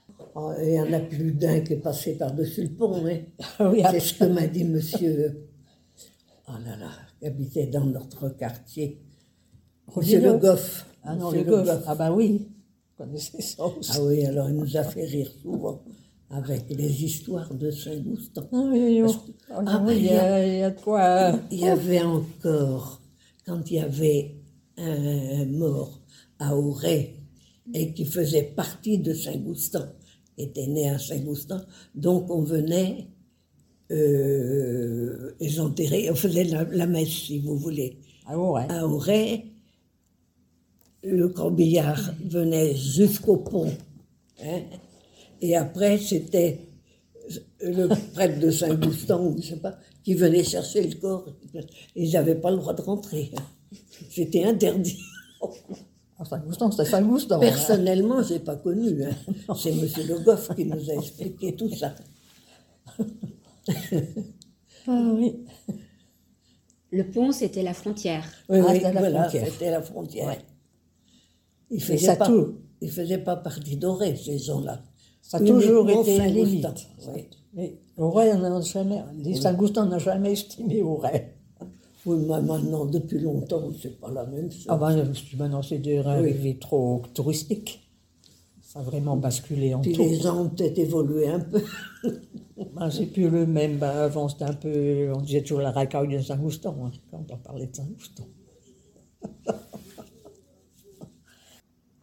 Témoignages audio